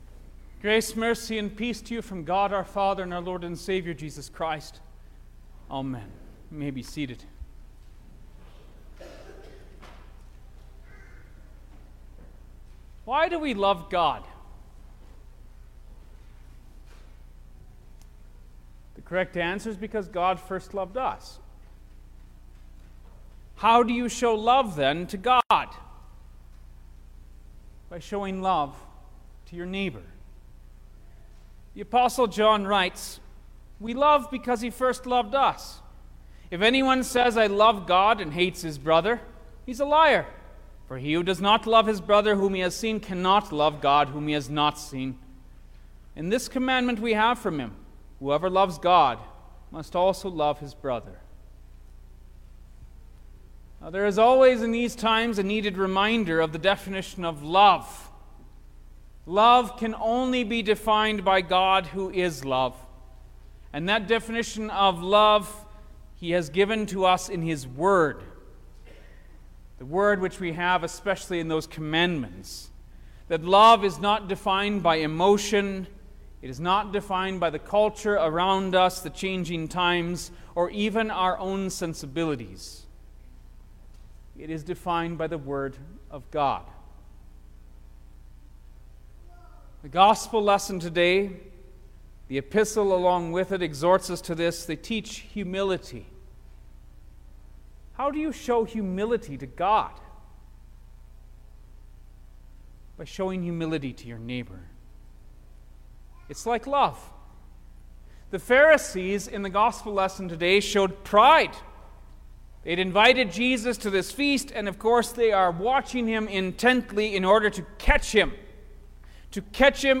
October-9_2022_The-Seventeenth-Sunday-after-Trinity_Sermon-Stereo.mp3